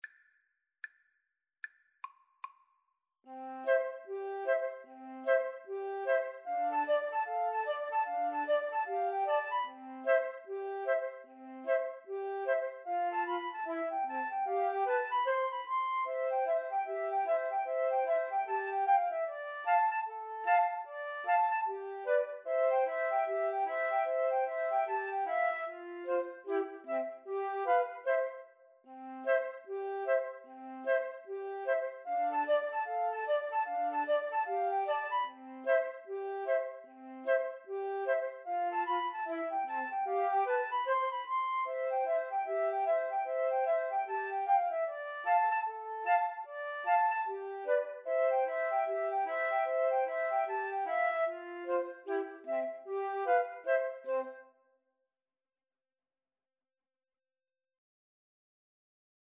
C major (Sounding Pitch) (View more C major Music for Flute Trio )
Flute Trio  (View more Intermediate Flute Trio Music)